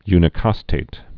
(ynĭ-kŏstāt)